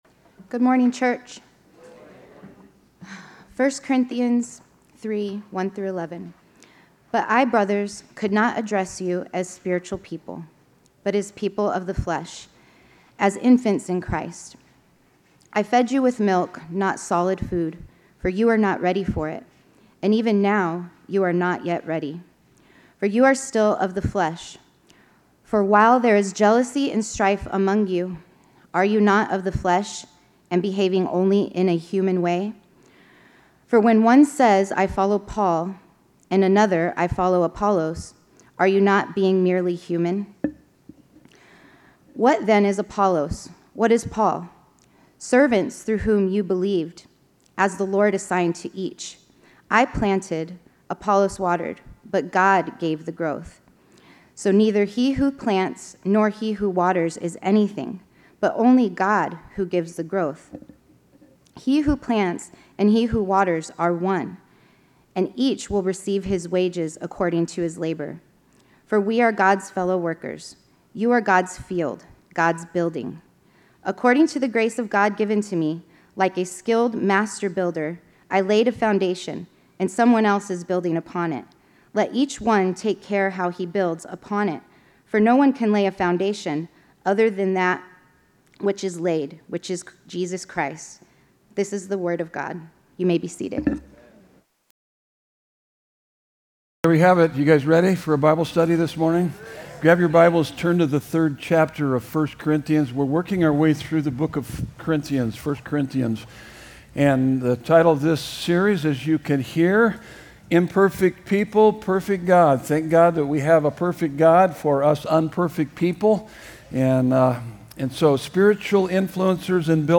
Sermon Notes: Spiritual Influencers and Builders